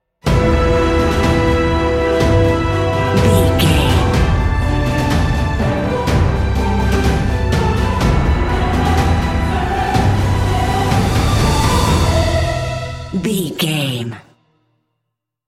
Uplifting
Aeolian/Minor
Slow
powerful
brass
cello
choir
strings
trumpet